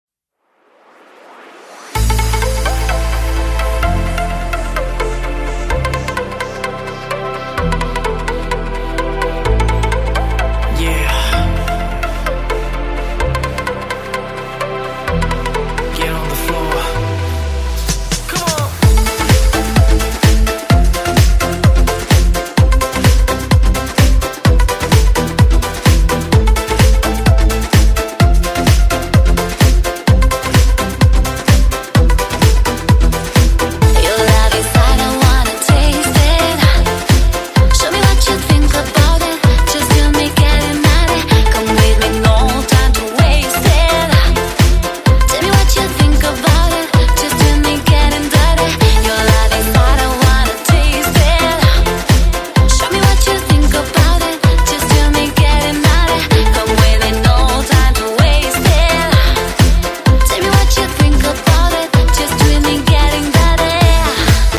• Качество: 128, Stereo
одна из новинок поп музыки